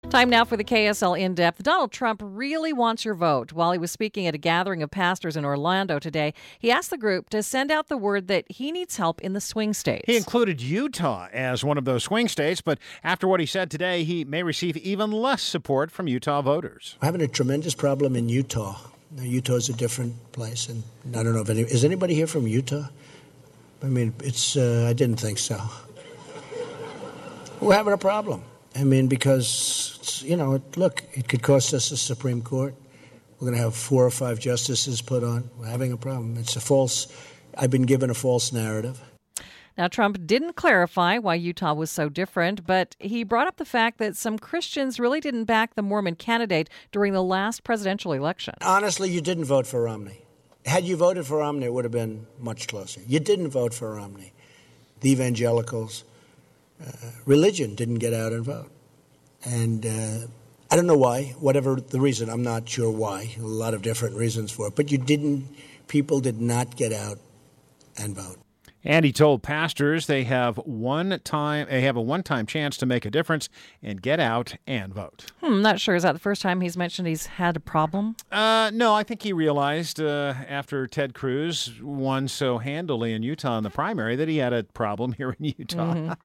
His speech in front of pastors was supposed to encourage people to vote for him. But he may have done just the opposite when mentioning the possible swing state of Utah.